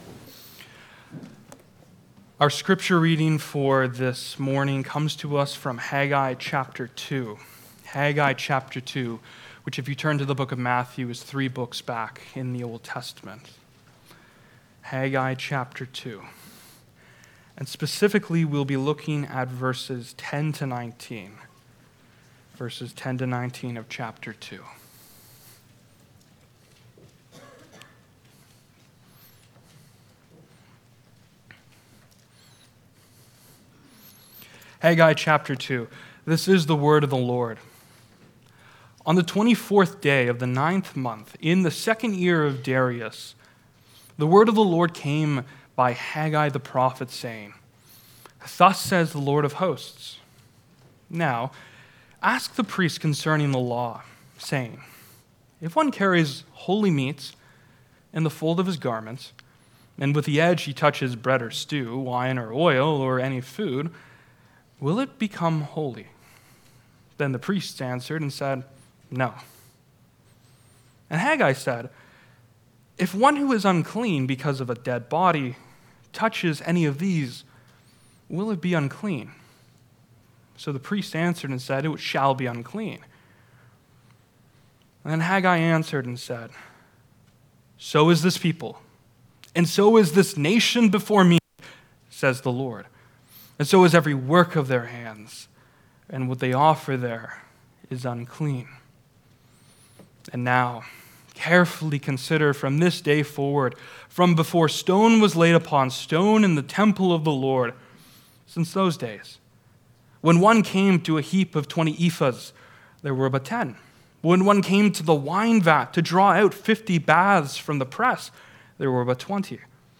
Haggai Passage: Haggai 2:10-20 Service Type: Sunday Morning « We Have the Resurrection Jesus Said